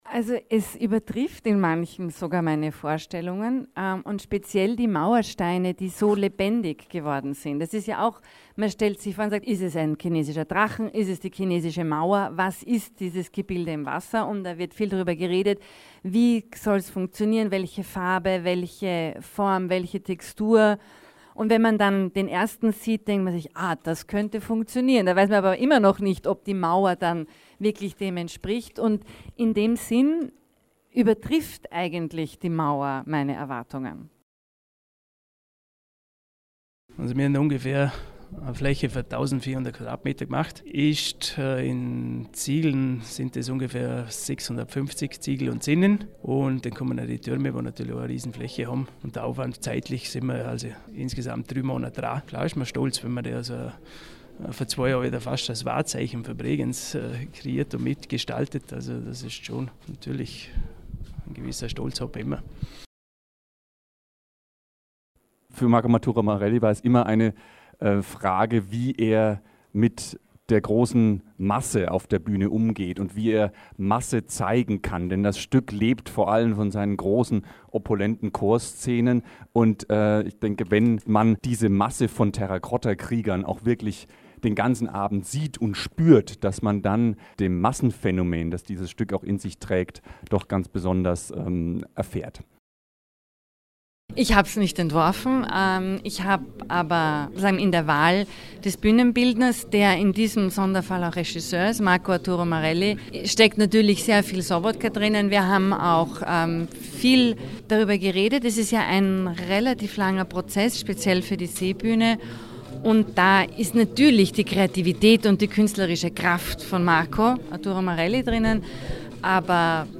O-Töne Fototermin Alberschwende - feature